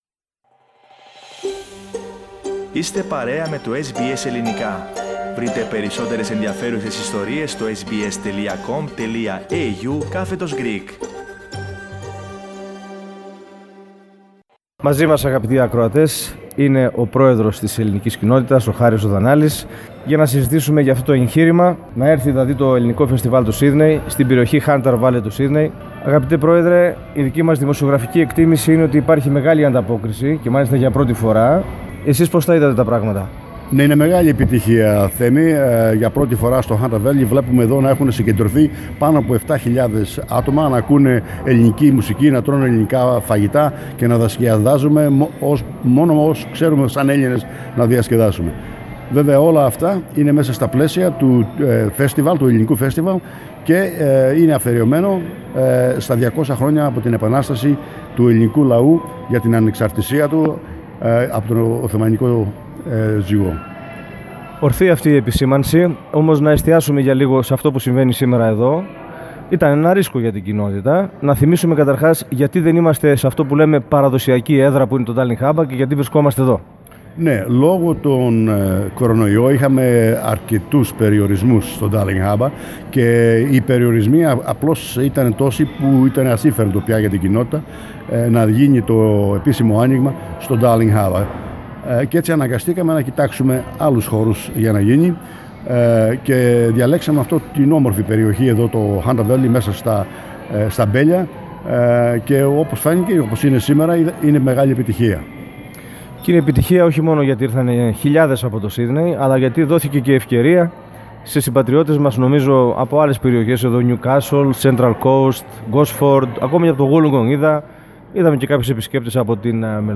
Το Ελληνικό Φεστιβάλ στο Hope Estate του Hunter Valley
Το SBS Greek/Ελληνικό Πρόγραμμα της Ραδιοφωνίας SBS ήταν εκεί.